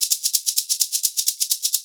Index of /90_sSampleCDs/USB Soundscan vol.36 - Percussion Loops [AKAI] 1CD/Partition B/22-130SHAKER